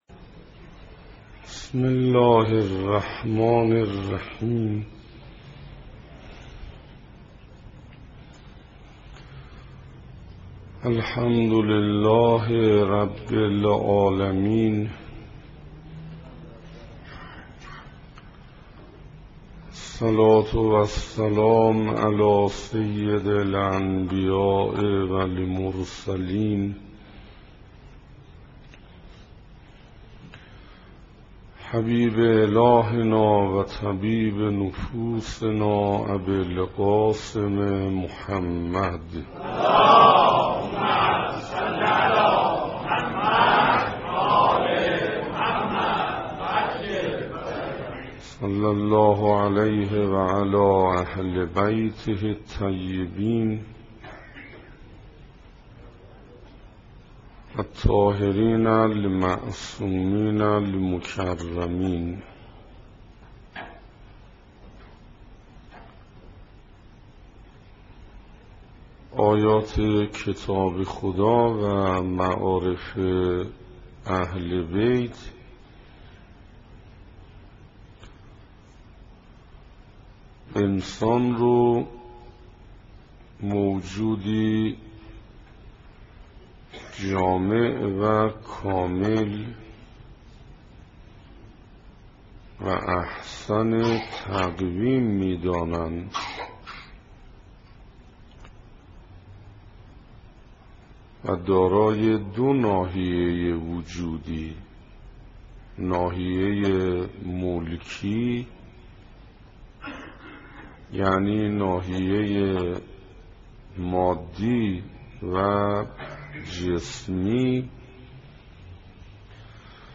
سخنراني پانزدهم
صفحه اصلی فهرست سخنرانی ها نگاهي به آيات قرآن (2) سخنراني پانزدهم (تهران بیت الزهرا (س)) رمضان1427 ه.ق - مهر1385 ه.ش دانلود متاسفم..